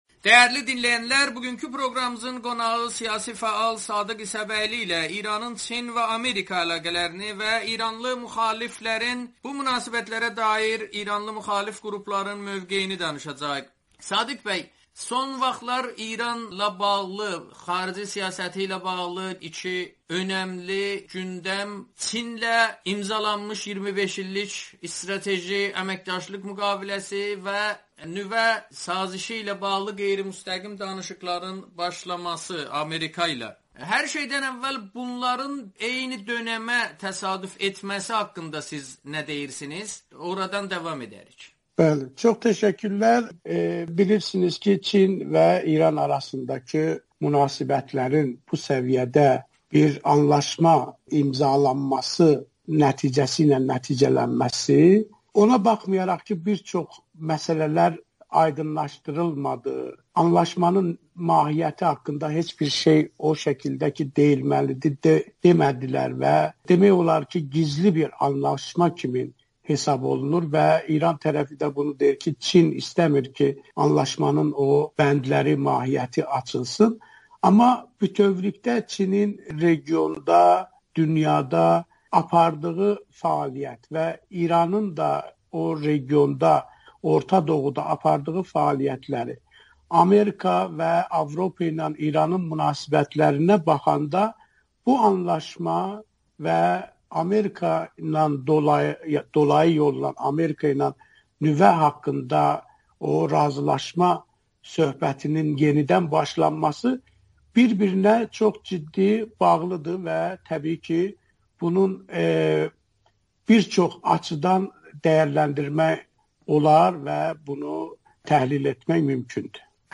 Amerikanın Səsi ilə söhbətdə